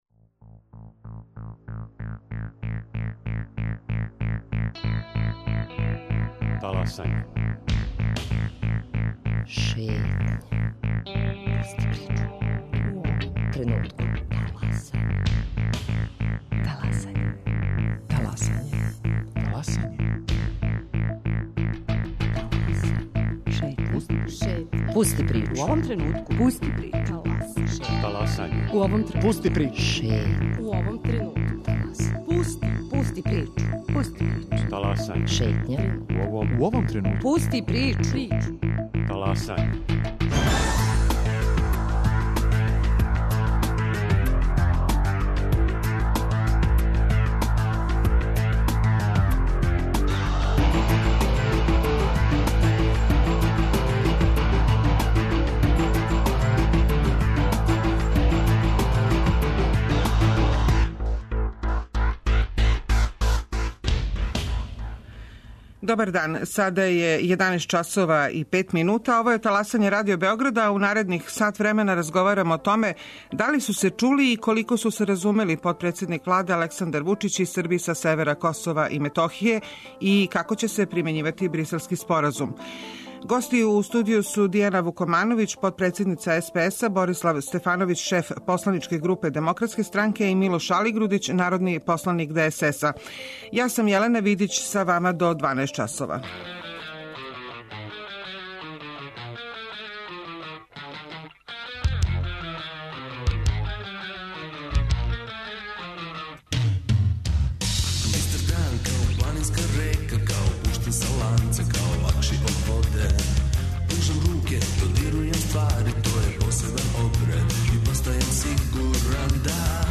Гости: Дијана Вукомановић, потпредседница СПС-а; Борислав Стефановић, шеф посланичке групе ДС-а и Милош Алигрудић, народни посланик ДСС.